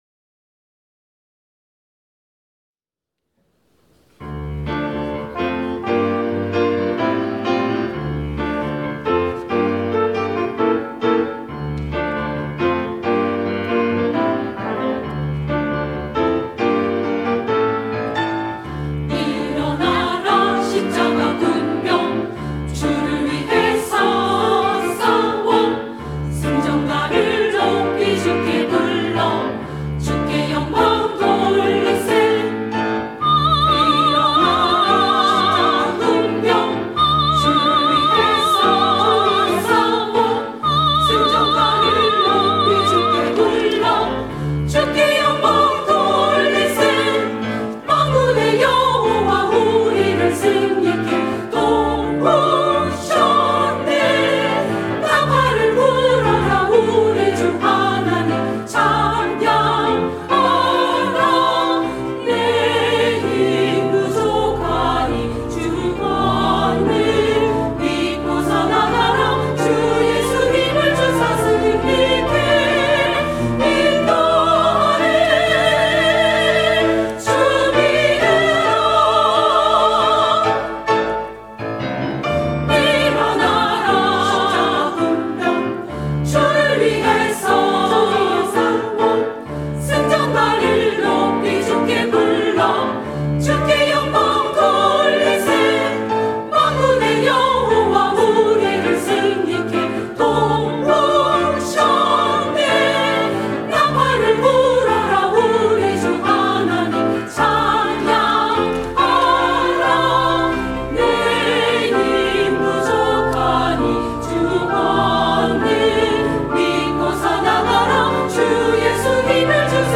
샤론